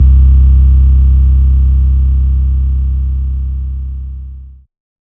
808s
SUB BOOM78.wav.wav